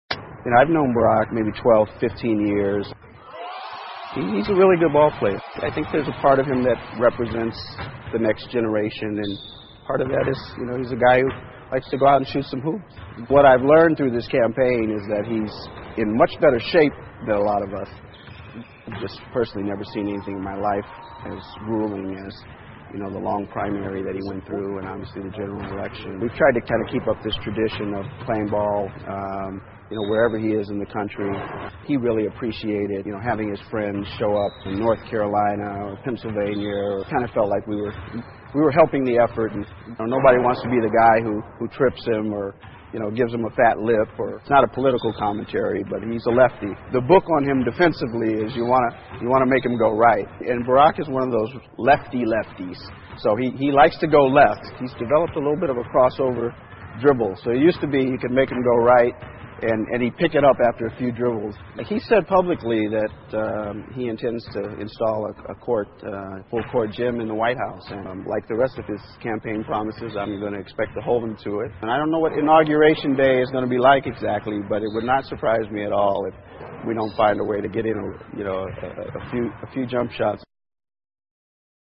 西部落：采访和奥巴马一起打球的兄弟 听力文件下载—在线英语听力室